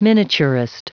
Prononciation du mot miniaturist en anglais (fichier audio)